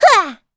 Gremory-Vox_Attack3.wav